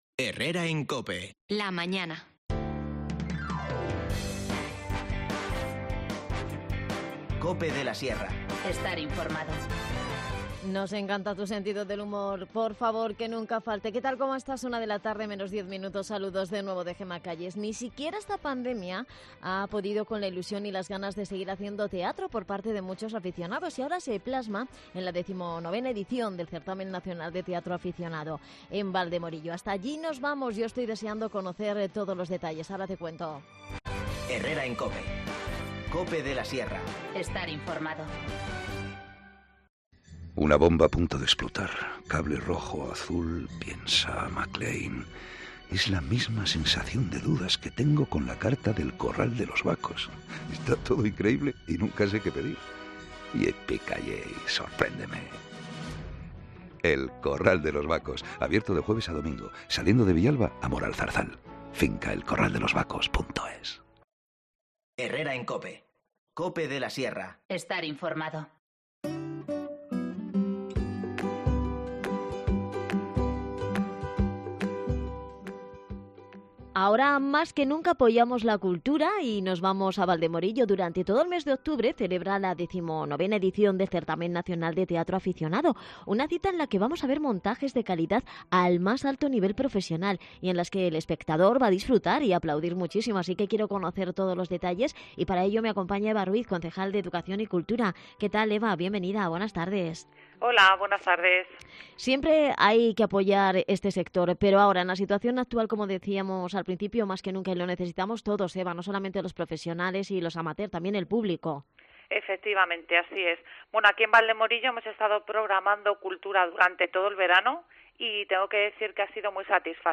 Hablamos con Ana Ruiz, concejal de Cultura y Educación